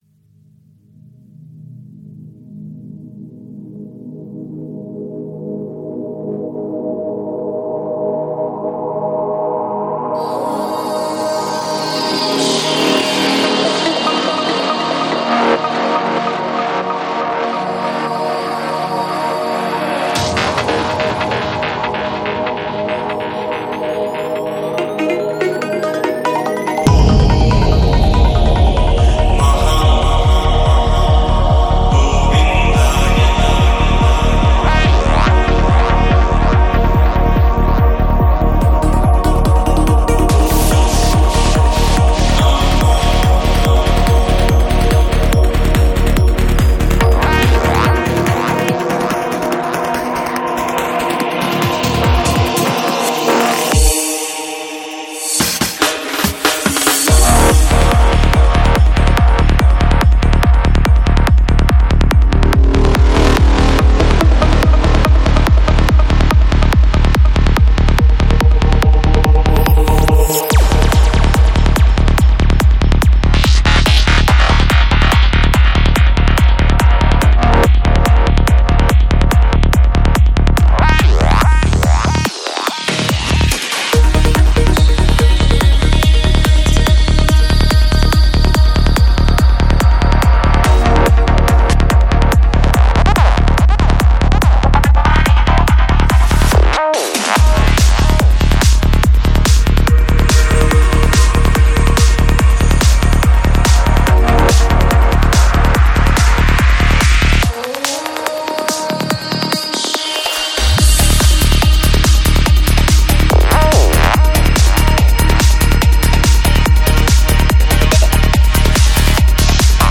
Жанр: Dance
Psy-Trance